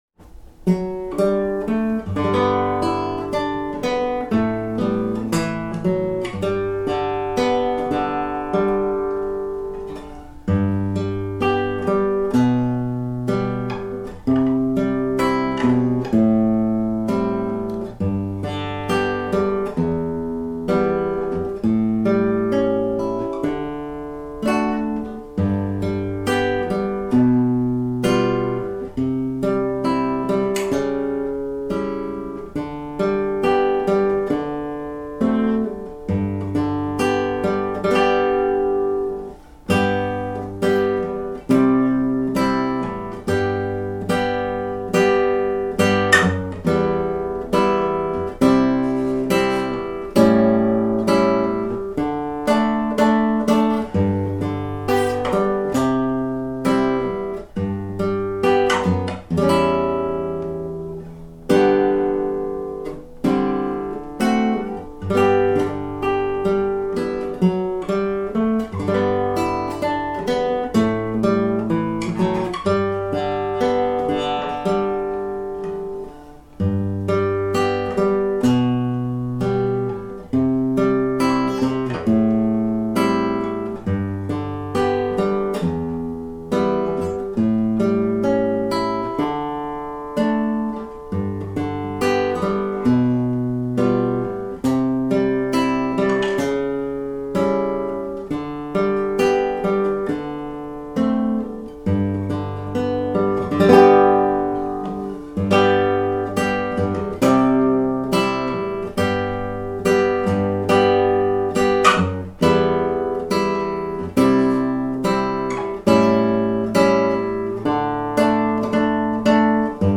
同曲のギター伴奏はこのようになっています。
（「ダニー･ボーイ」のギター伴奏音源
519-danny boy guitar.MP3